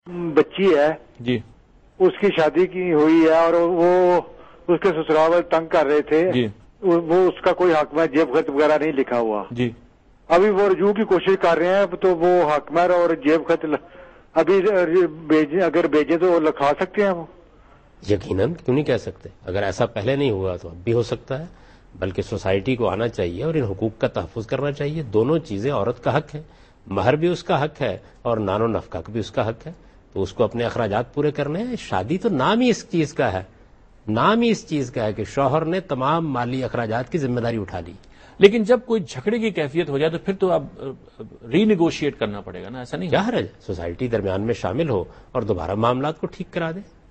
Javed Ahmad Ghamidi answers a question about "Promise of Pocket Money at the time of Marriage" in program Deen o Daanish on Dunya News.